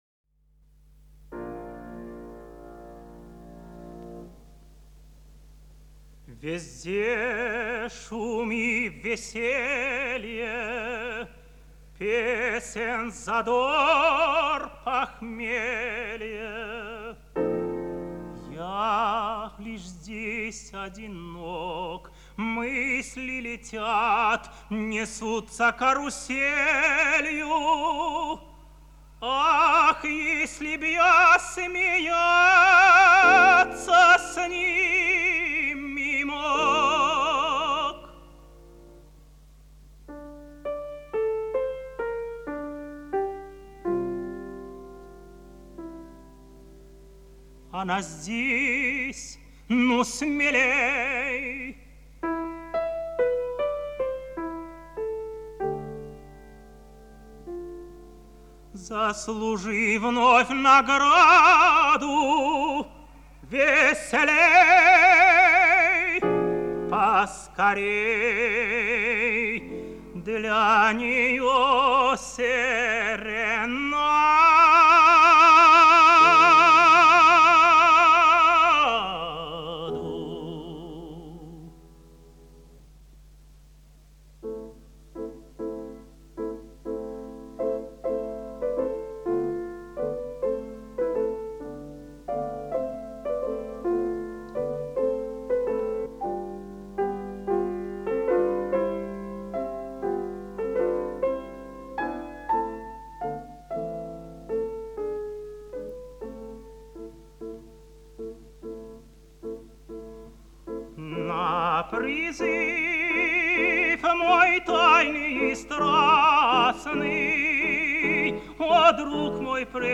06 - Соломон Хромченко - Серенада Смита из оперы Пертская красавица, 2 д. (Ж.Бизе) (1943)